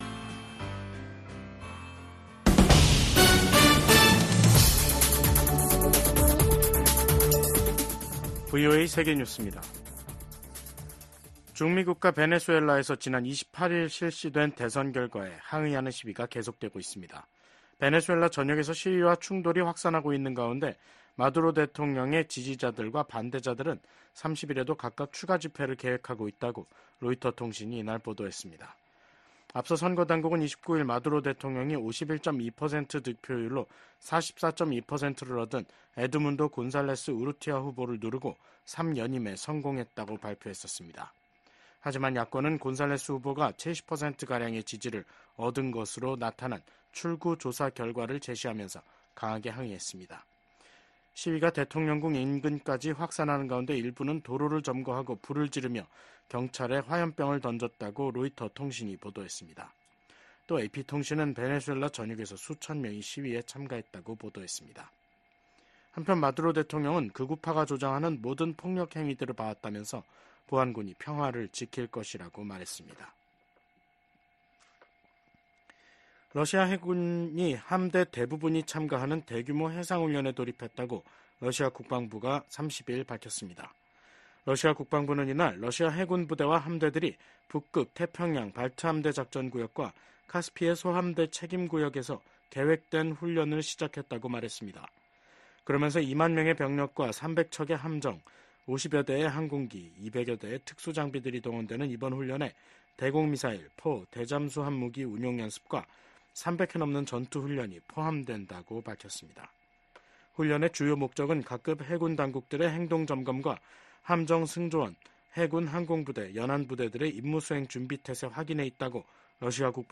VOA 한국어 간판 뉴스 프로그램 '뉴스 투데이', 2024년 7월 30일 3부 방송입니다. 미국이 핵확산금지조약(NPT) 평가 준비회의에서 북한의 완전한 비핵화가 목표라는 점을 재확인했습니다. 미국과 일본, 인도, 호주 4개국 안보협의체 쿼드(Quad) 외무장관들이 북한의 탄도미사일 발사와 핵개발을 규탄했습니다. 북한 주재 중국대사가 북한의 ‘전승절’ 기념 행사에 불참하면서, 북한과 중국 양국 관계에 이상 징후가 한층 뚜렷해지고 있습니다.